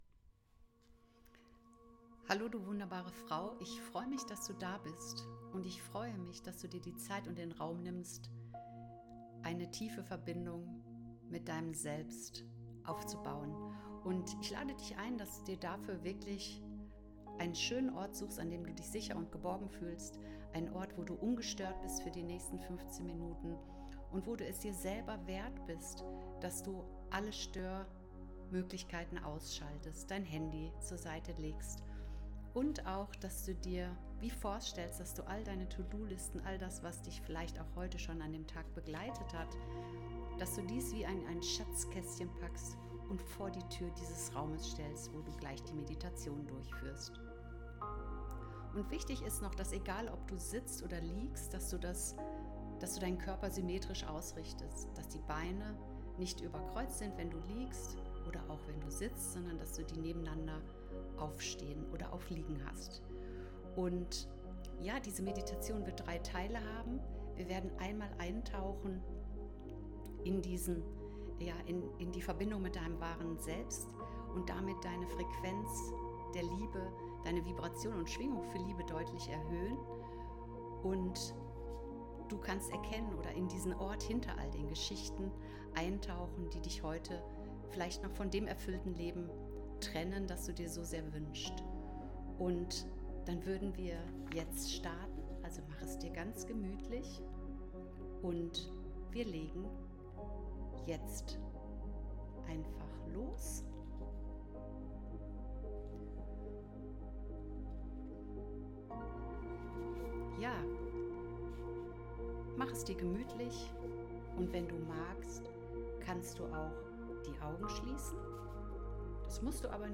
‘Rendevous mit deinem wahren Ich’ Die Meditation zum Buch